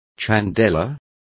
Complete with pronunciation of the translation of chandeliers.